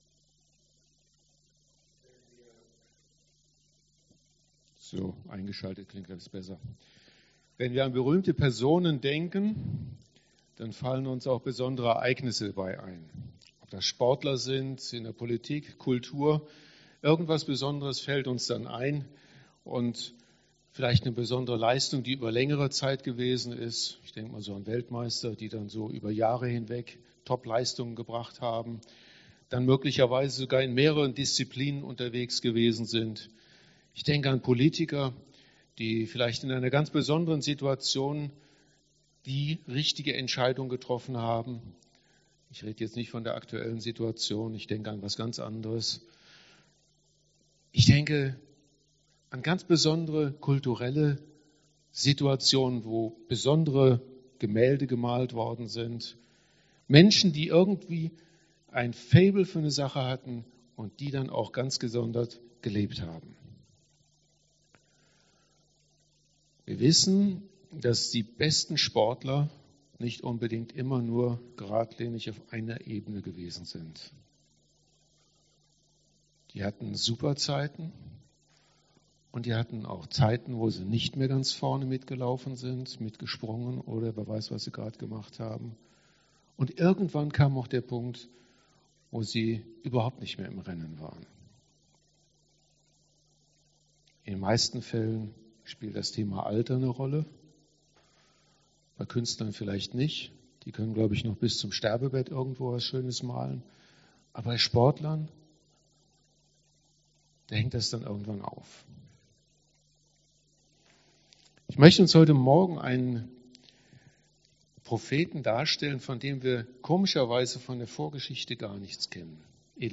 Predigt 25.10.2020